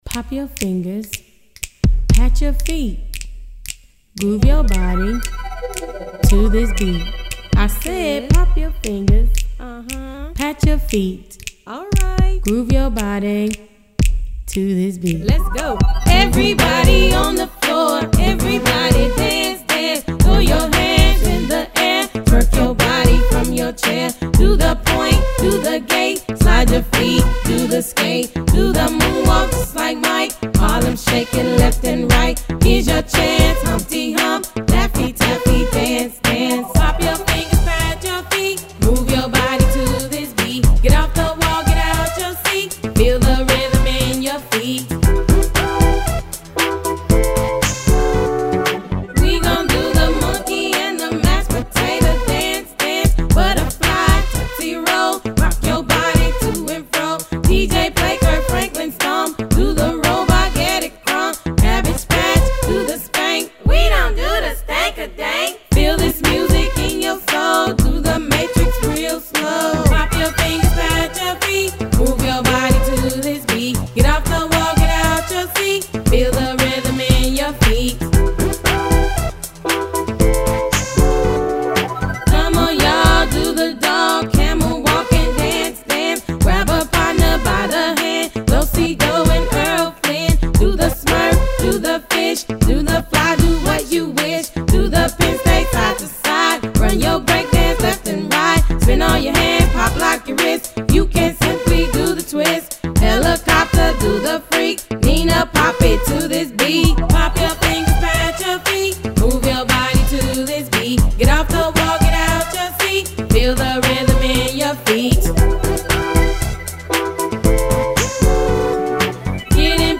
dance/electronic
RnB
Rap
Hip-hop